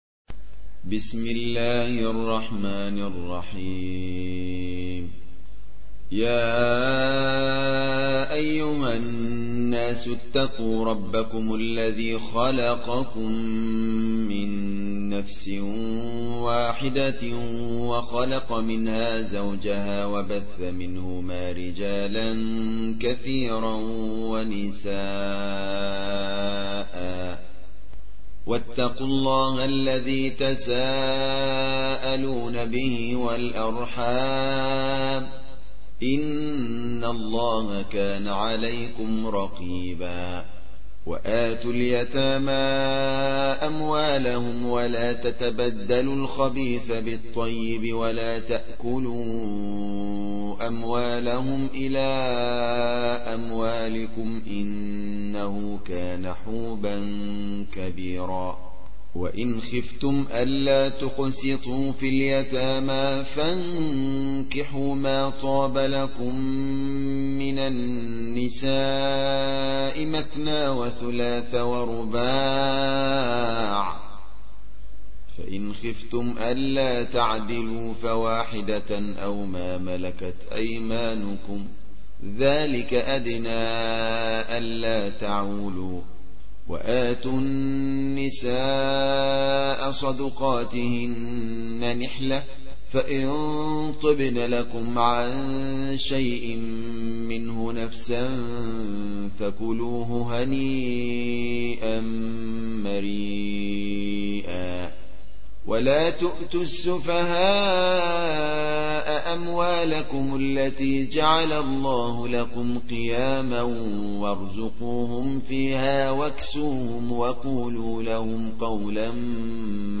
4. سورة النساء / القارئ